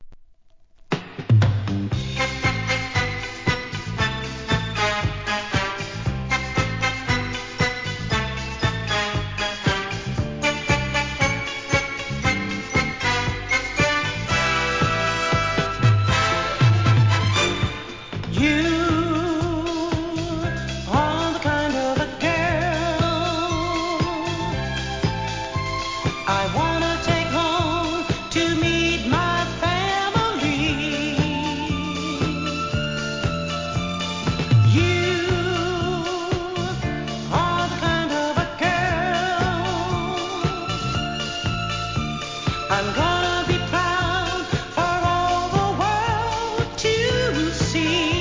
¥ 440 税込 関連カテゴリ SOUL/FUNK/etc...